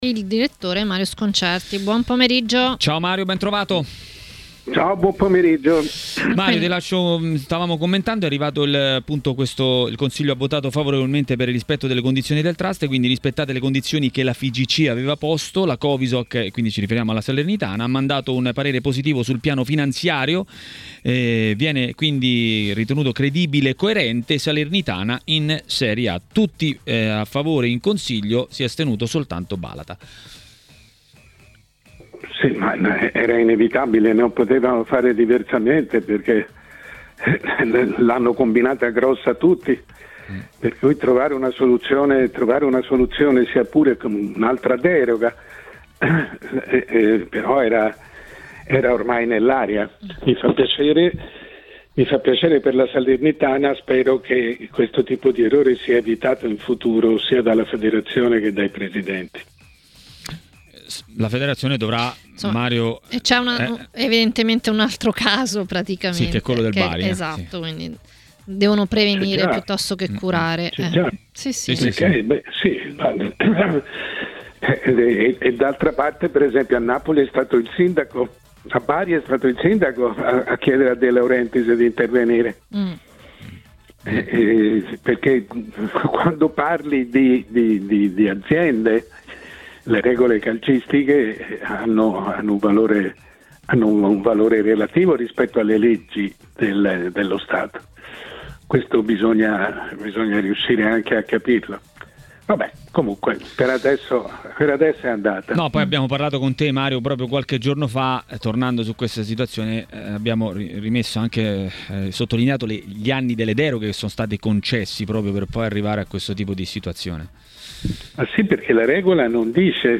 Il direttore Mario Sconcerti è intervenuto a TMW Radio, durante Maracanà, per parlare di Euro 2020 e non solo.